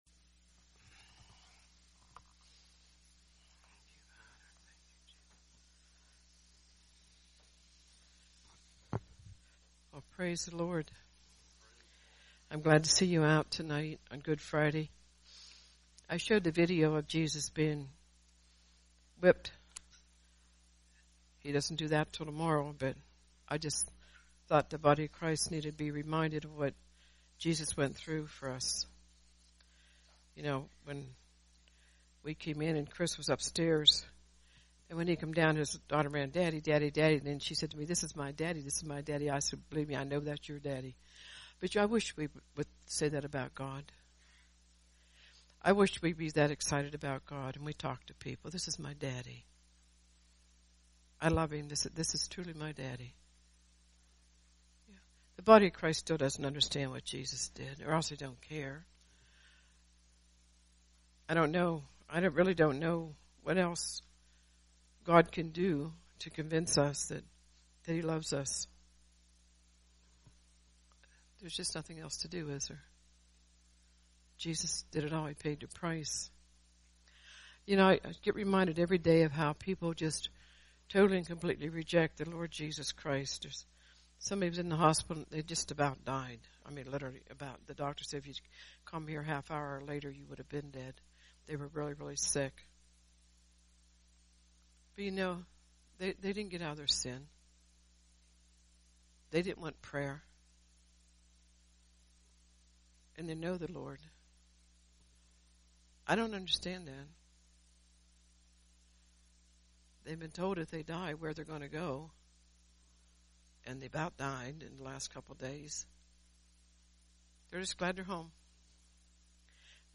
Good Friday – Communion
Sermons